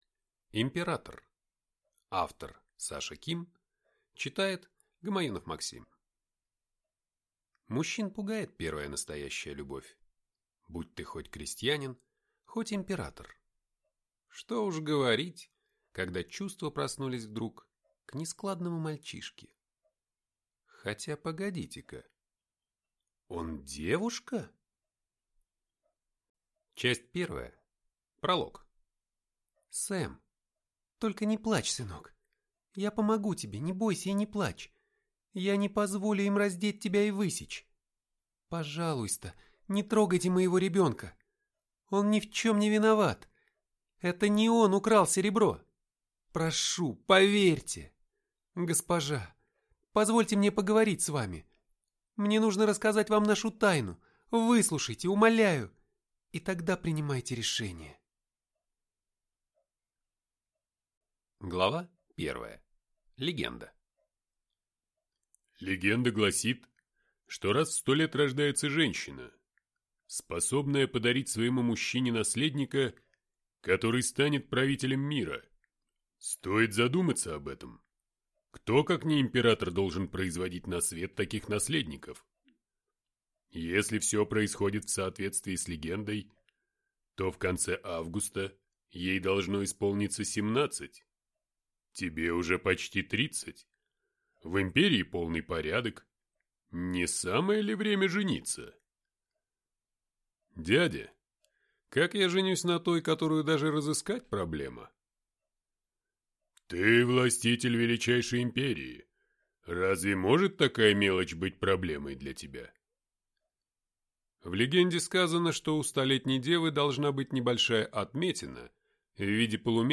Аудиокнига Император | Библиотека аудиокниг